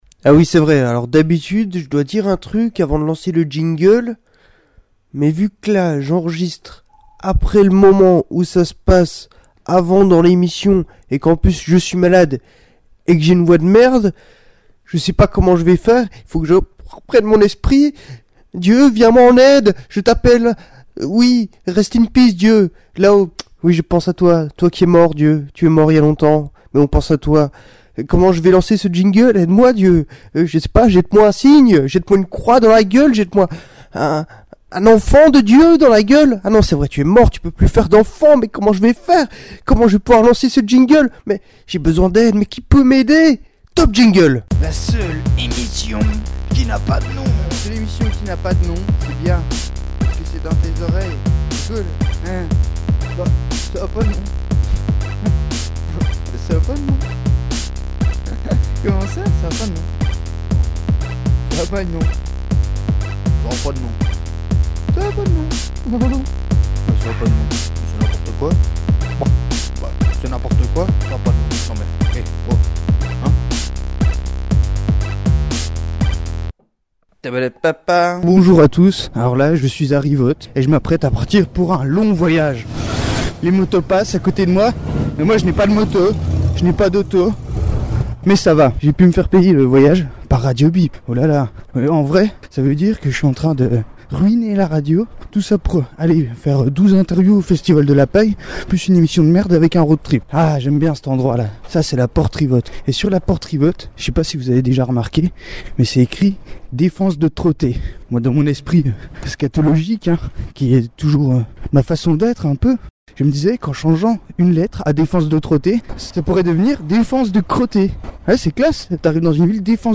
Ainsi, dans cette émission vous visiterez Rivotte, le Bus Monts-Jura qui fait Besançon-Pontarlier, Pontarlier, bien entendu, et son accueillant commissariat, le lac Saint-Point… euh de Malbuisson, Le Coude, la voiture des personnes qui m’ont pris en auto-stop, et moults gens qui ont croisé mon chemin.